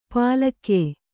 ♪ pālakye